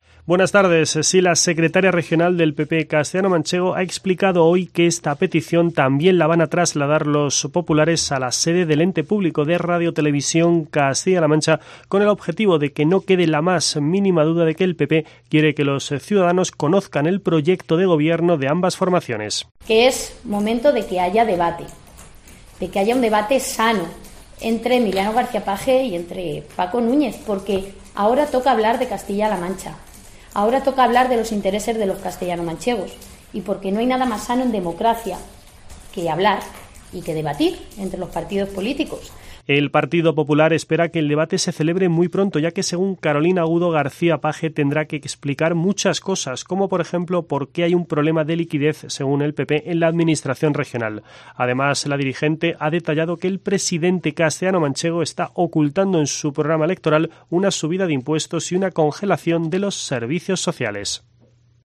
Así lo ha avanzado en rueda de prensa la secretaria regional del PP, Carolina Agudo